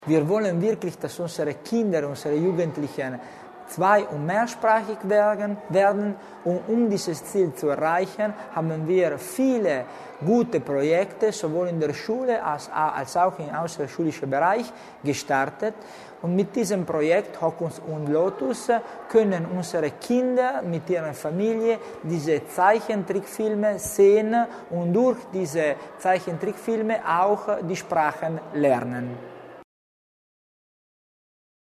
Landeshauptmannstellvertreter Tommasini zur Bedeutung des Projekts